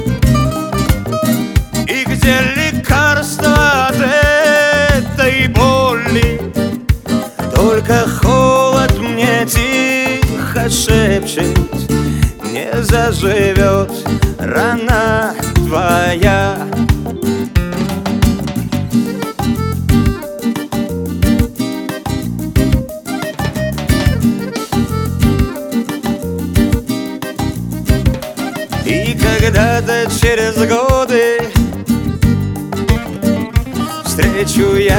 Жанр: Русская поп-музыка / Русский рок / Русские
# Chanson in Russian